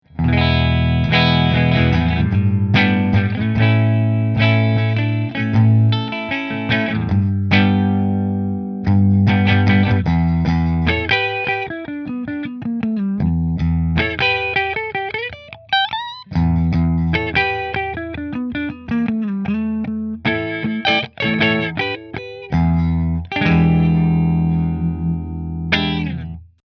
Greco Les Paul Custom White Replica Middle Through Fender